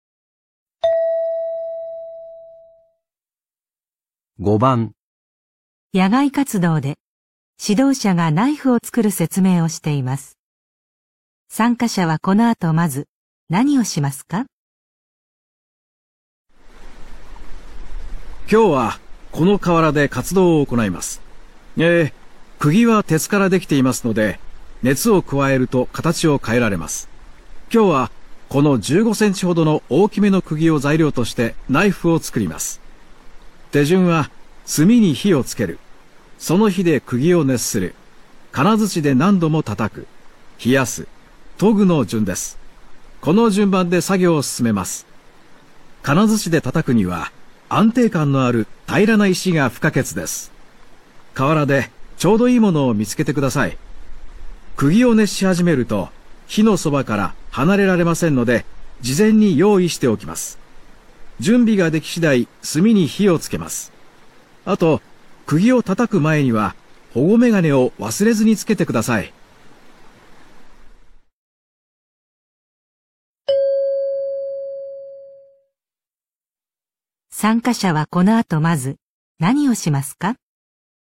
野外活动的指导员正在说明小刀的制作方式。
野外活動で指導者がナイフを作る説明をしています。